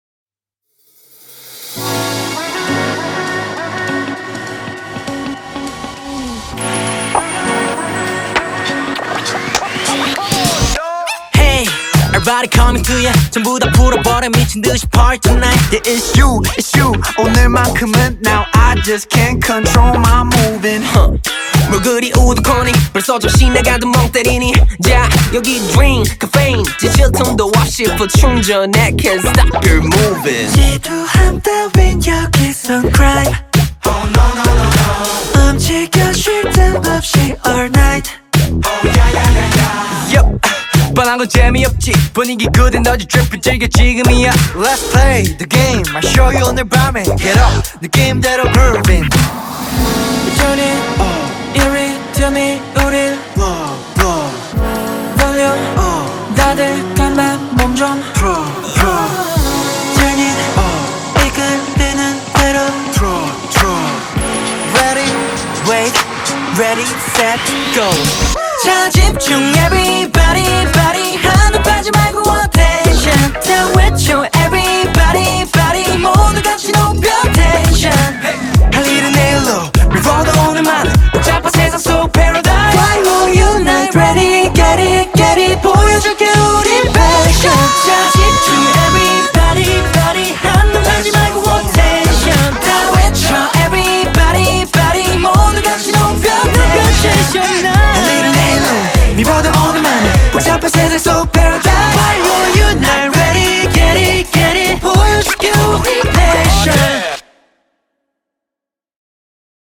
BPM100
MP3 QualityMusic Cut